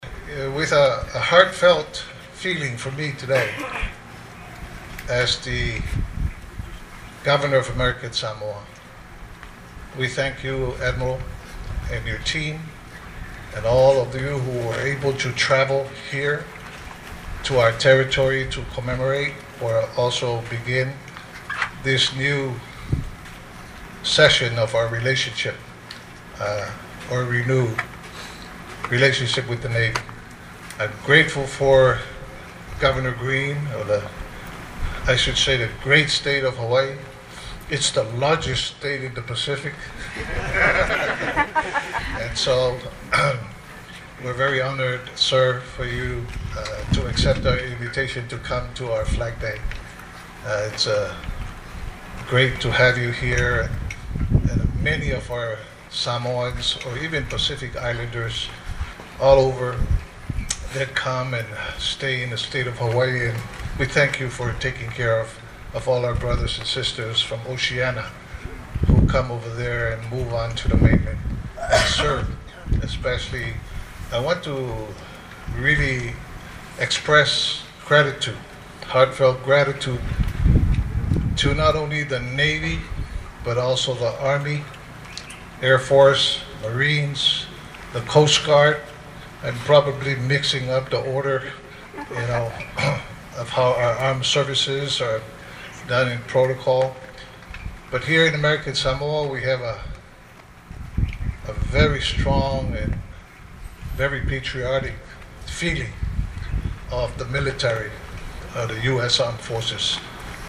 The first celebration of U.S. Navy Week in American Samoa kicked off this afternoon with a ceremony at the Veterans Monument.
Governor Pulaalii extended a warm welcome to Admiral Meyer and the U.S. naval team, also paying tribute to other branches of the U.S. Armed Forces.